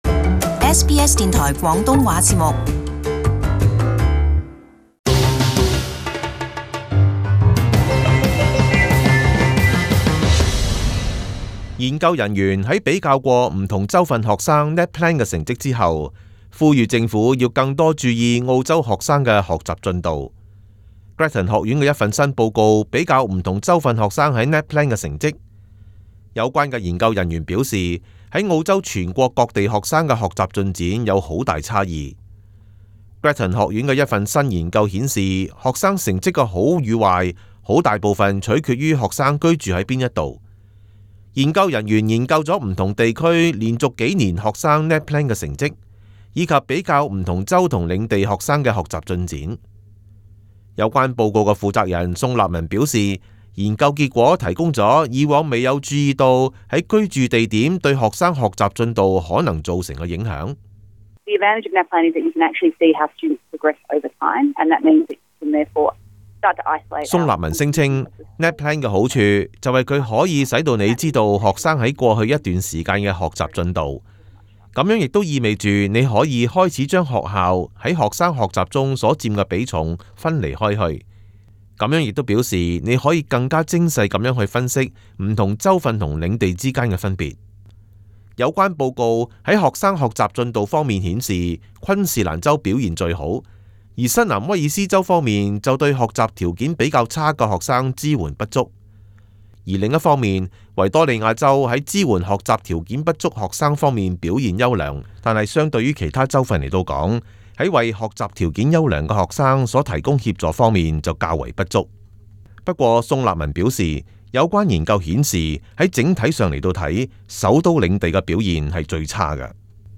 【時事報導】不同州及領地學生學習進度有差別